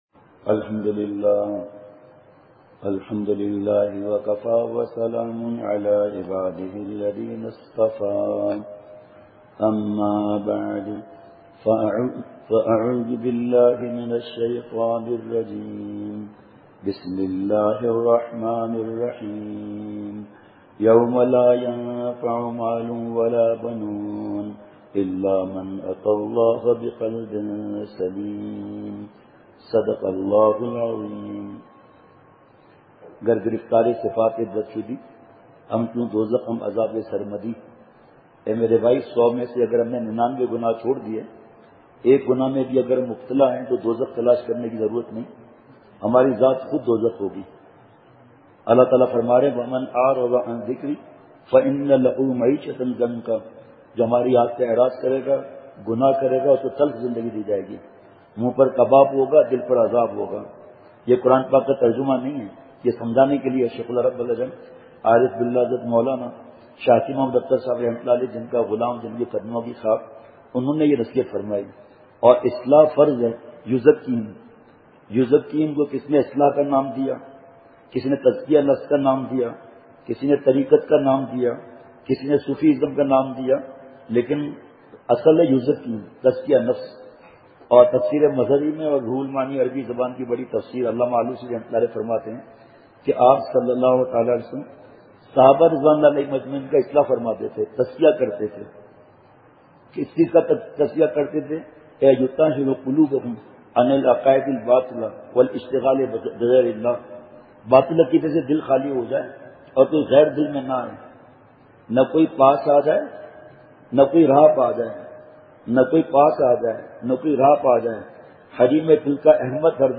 بمقام: جامع گول مسجد زرغون آباد کوئٹہ۔۔
حضرت جگر مراد آبادی رحمۃ اللہ علیہ کا واقعہ حضرت ابرہیم بن ادہم رحمتہ اللہ علیہ کا واقعہ بہت اشکبار آنکھوں سے دعا۔ پوری مسجد بھری ہوئی تھی ہرآنکھ اشکبار تھی۔ مجمع پر گریہ طاری تھا۔